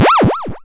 powerup2.wav